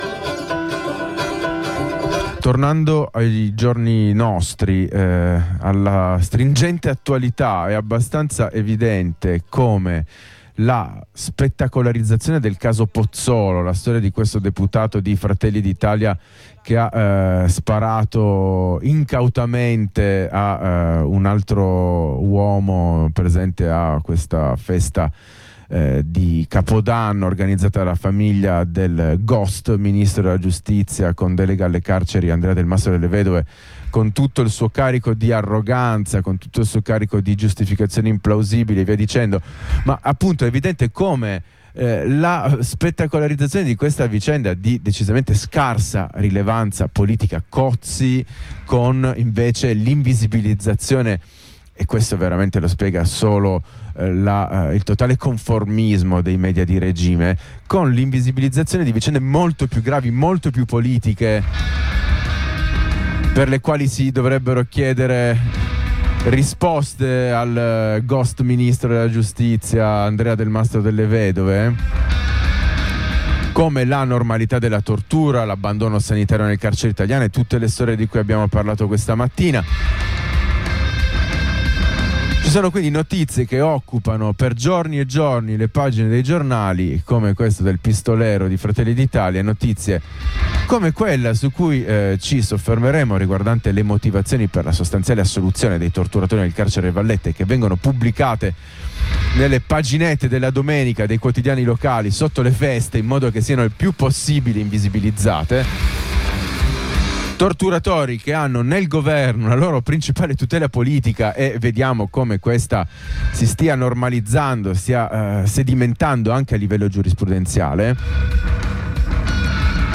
Cerchiamo di osservare cosa sia avvenuto negli ultimi giorni a Santa Maria Capua Vetere, Agrigento e Caltanissetta, anche grazie alla testimonianza diretta della moglie di un uomo detenuto nell’ultima struttura citata: